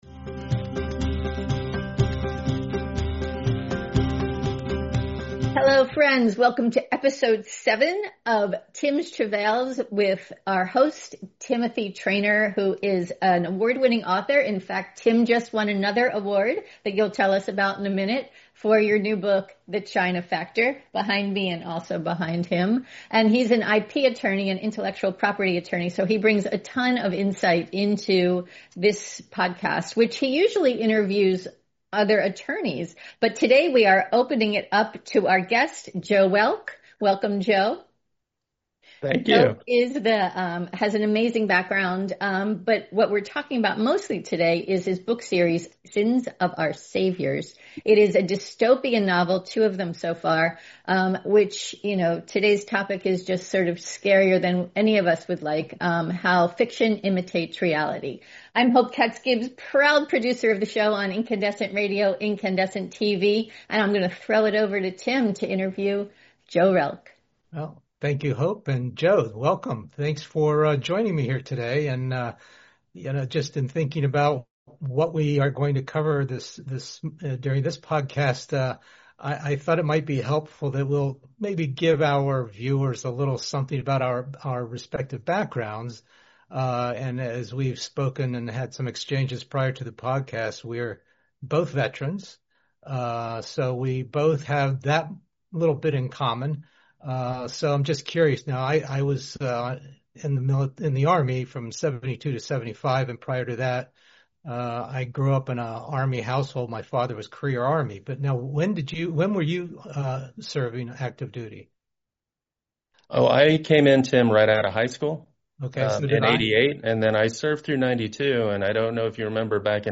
Podcast Special